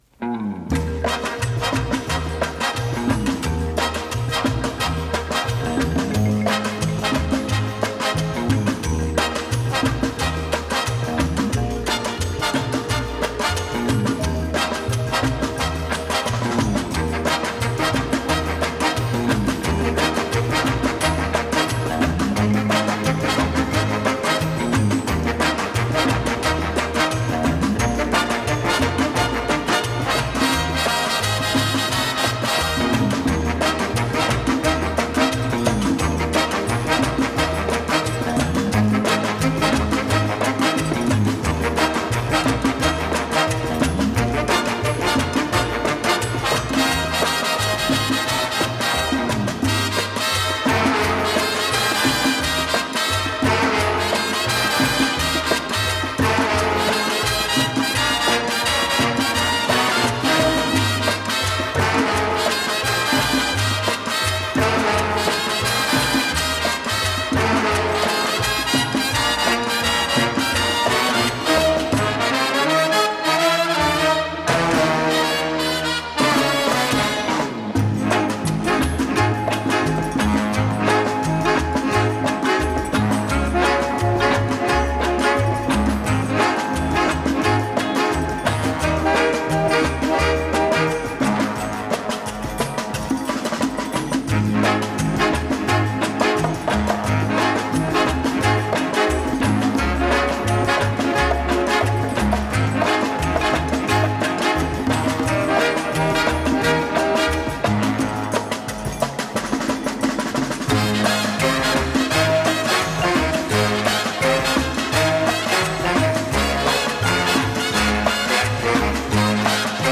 Жанр: Easy Listening, Twist